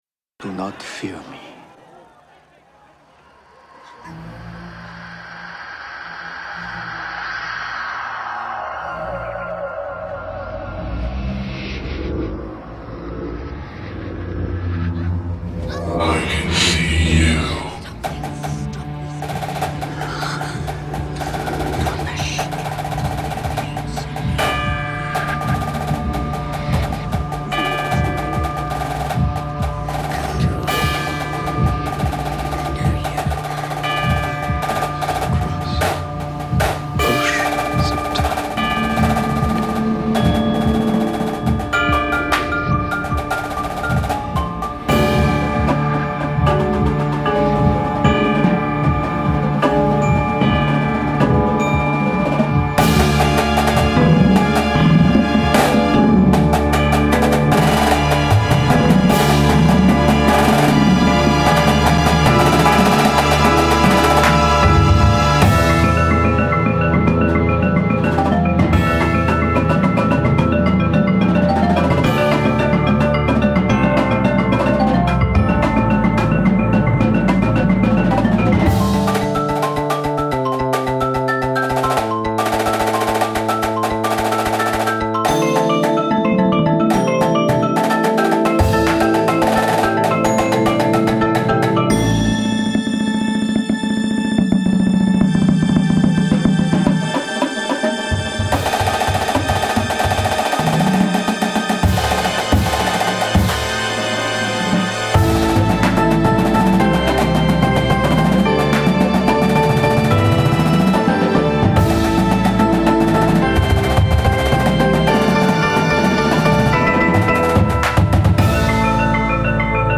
• Chimes/Crotales
• Glockenspiel
• Xylophone
• 2 Marimbas (4 or 5 option)
• 2 Synthesizers
• Snareline